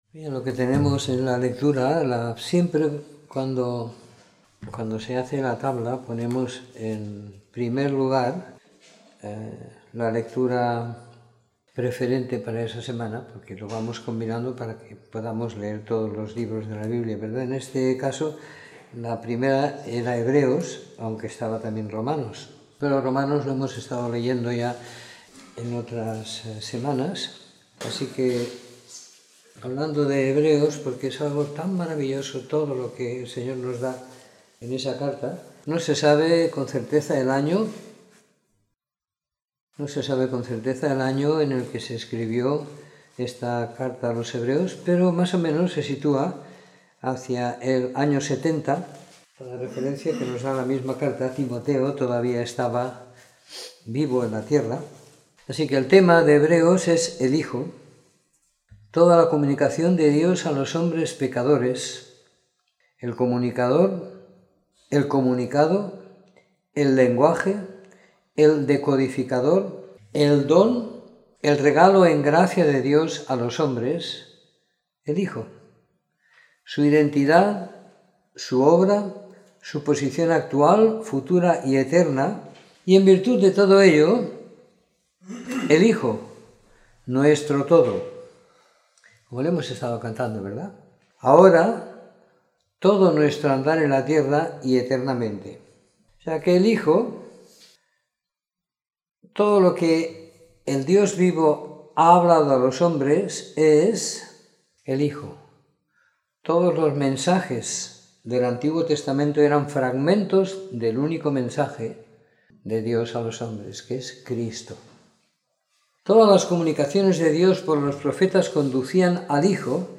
Comentario en los libros de Hebreos del capítulo 1 al 13 y Romanos del capítulo 9 al 16 siguiendo la lectura programada para cada semana del año que tenemos en la congregación en Sant Pere de Ribes.